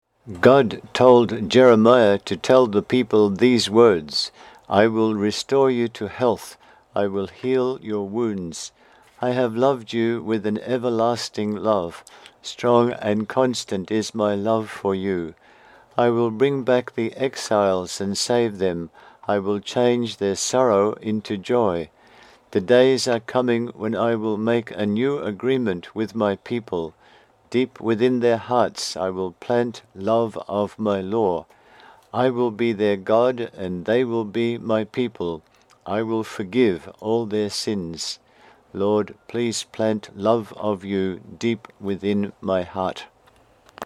My recording of this reading